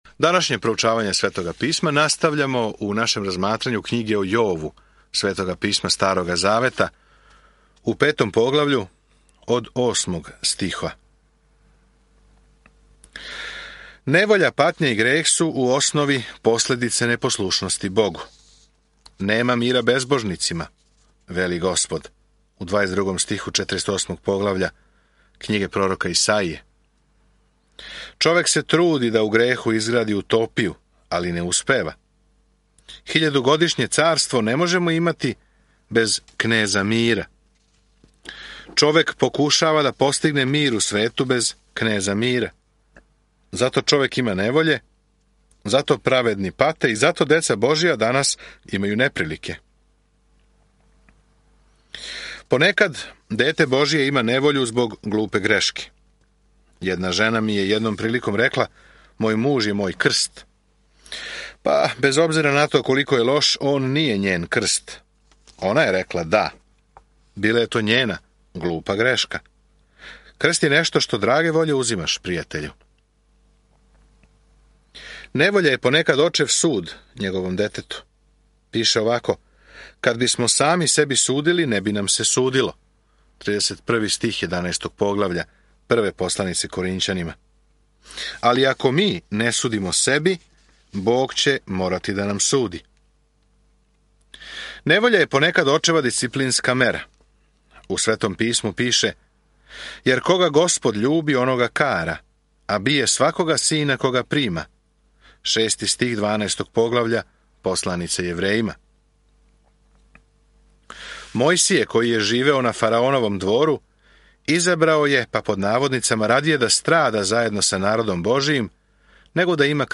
Sveto Pismo Knjiga o Jovu 5:5-27 Knjiga o Jovu 6 Knjiga o Jovu 7:1-16 Dan 5 Započni ovaj plan Dan 7 O ovom planu У овој драми неба и земље срећемо Јова, доброг човека, кога је Бог дозволио да нападне Сатана; сви имају толико питања о томе зашто се лоше ствари дешавају. Свакодневно путујте кроз Јов док слушате аудио студију и читате одабране стихове из Божје речи.